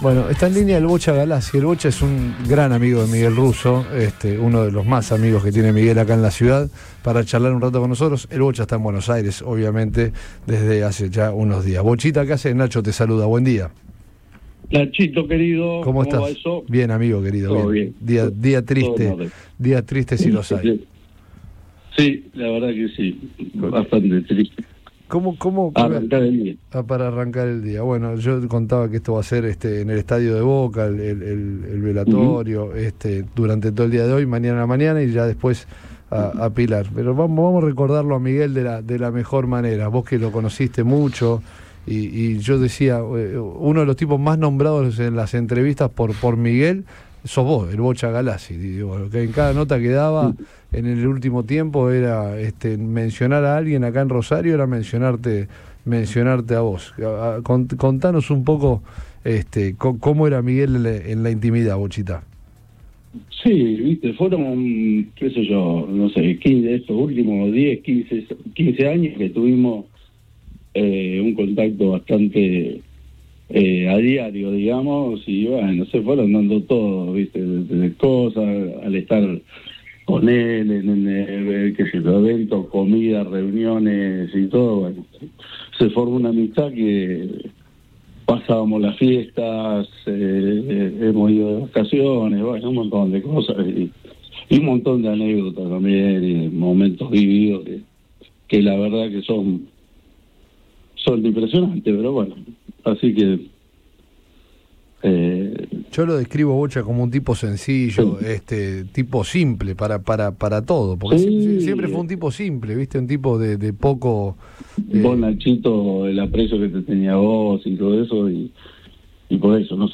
En diálogo con Radio Boing…